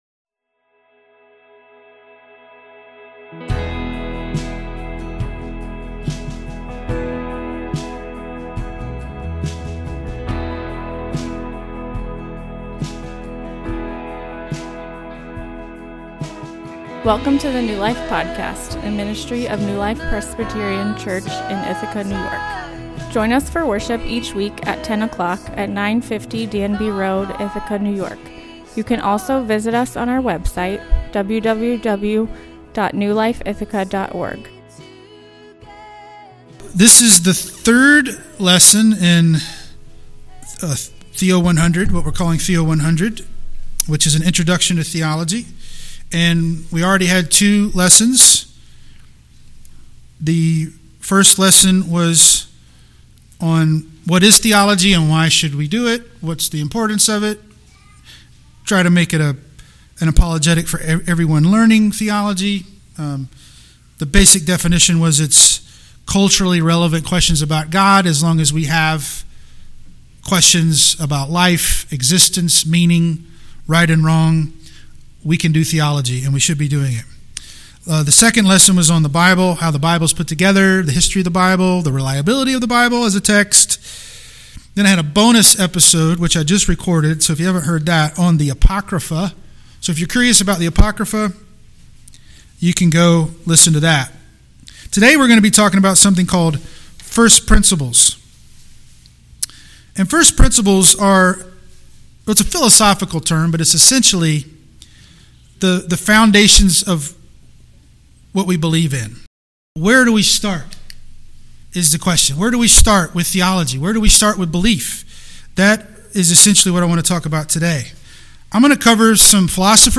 This is the third class in a five week Christian education class called Theo 100, an introduction to Christian Theology. This week we talk about the fundamental building blocks of our faith, our First Principles. Here we discuss how we approach theology if belief in God and the Bible is not something we take for granted, or have doubts in.